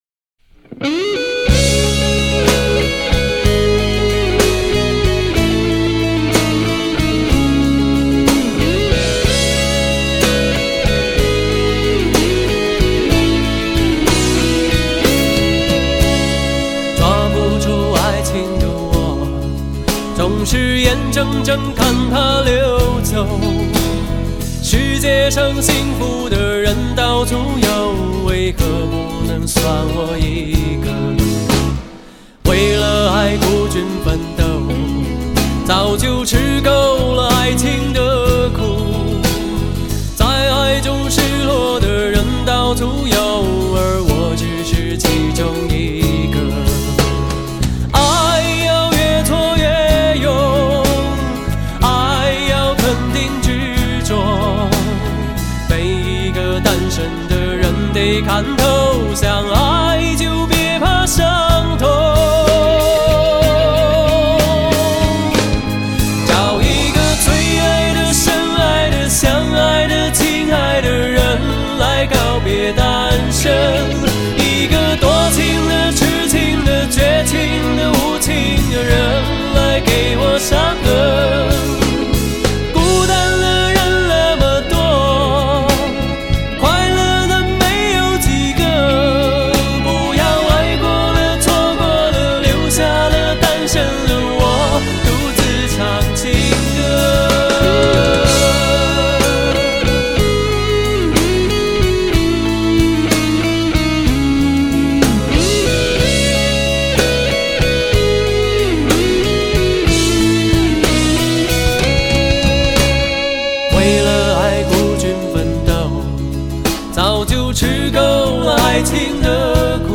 极富视听效果的发烧靓声，德国版HD高密度24BIT数码录音。